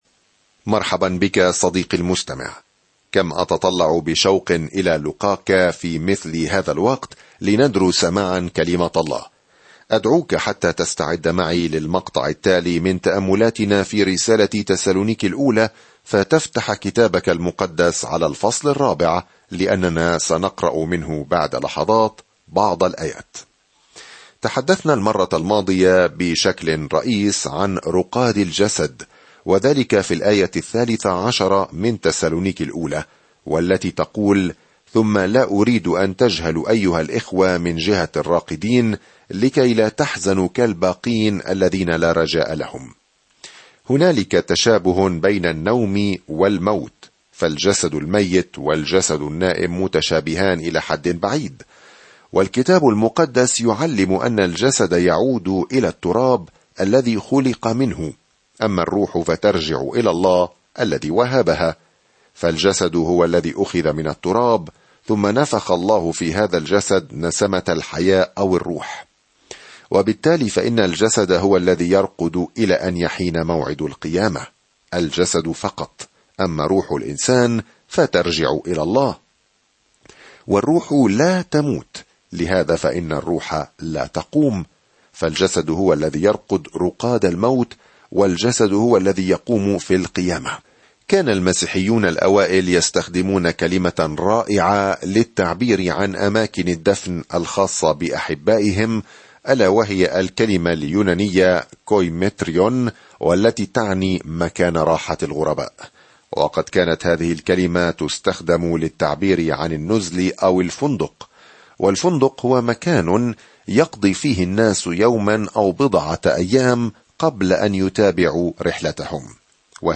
سافر يوميًا عبر رسالة تسالونيكي الأولى وأنت تستمع إلى الدراسة الصوتية وتقرأ آيات مختارة من كلمة الله.